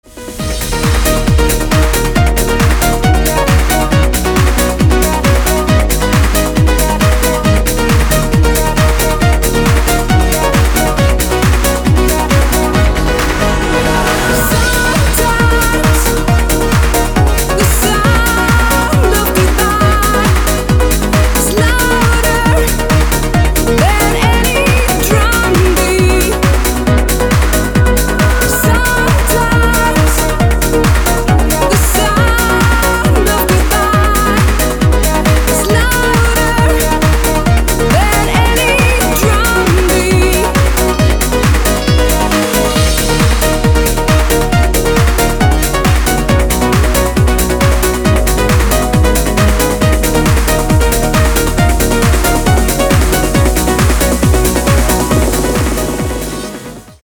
• Качество: 256, Stereo
громкие
dance
Electronic
электронная музыка
club
красивый женский голос
Trance
vocal trance